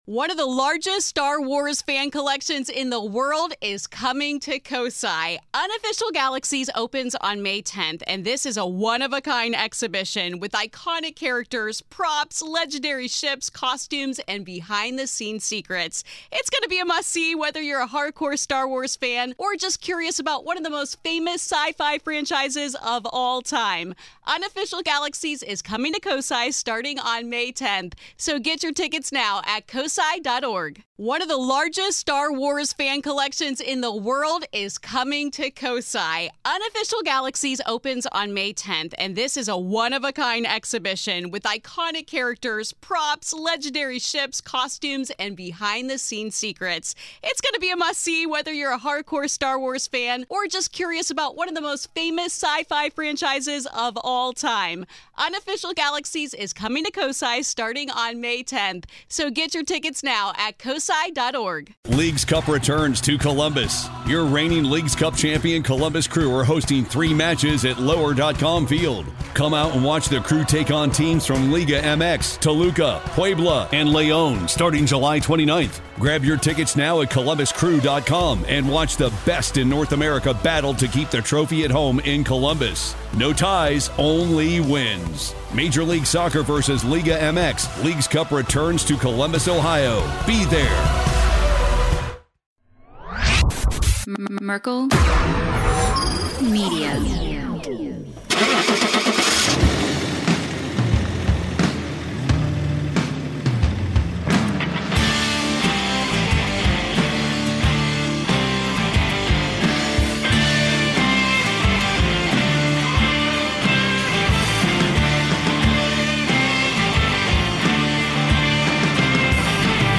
This conversation was incredible, and so were the many stories he shared with the guys.